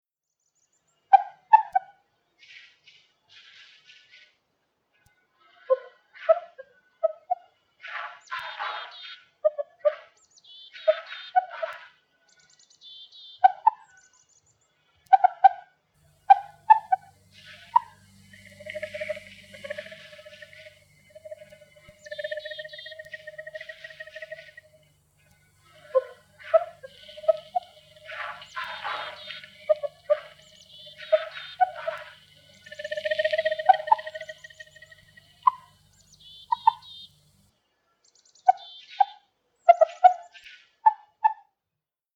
Mauswiesel Paarungslaute - Heintges Lehr- und Lernsystem GmbH
Mauswiesel-Paarungslaute.mp3